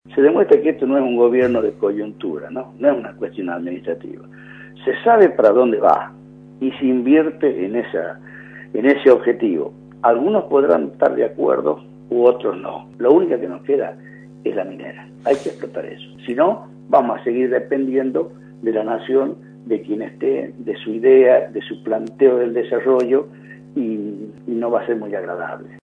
En declaraciones a Radio La Red, el legislador destacó que este tipo de actividad traerá buenos dividendos para las provincias por ser un mercado seguro y con buenas tasas de ganancias.